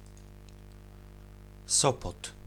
Sopot (pronounce: Missing image
['sɔpɔt], Kashubian: Sopót, German Zoppot) is a town on the South coast of the Baltic Sea in a metropolitan agglomeration called the Tricity (Gdańsk, Gdynia and Sopot) with approximately 40,000 inhabitants.